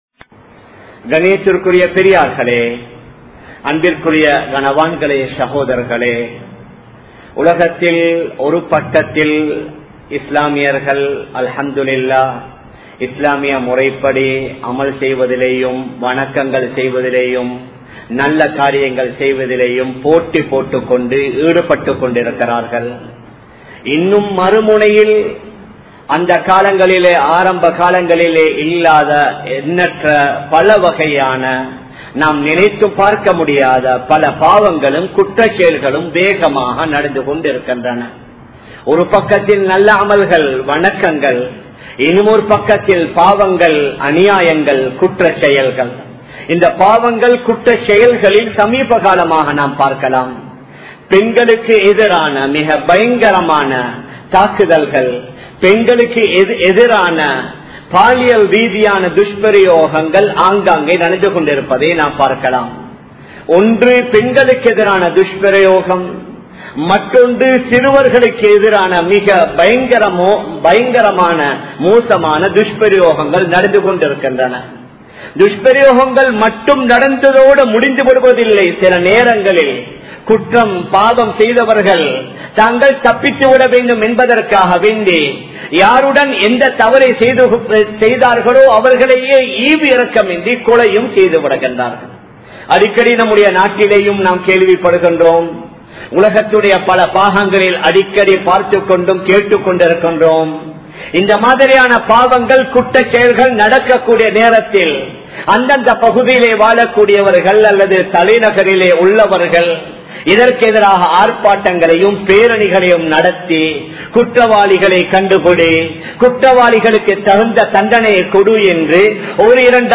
Manaiviyai Paathahaakka Theriyaatha Kanavan (மணைவியை பாதுகாக்க தெரியாத கணவன்) | Audio Bayans | All Ceylon Muslim Youth Community | Addalaichenai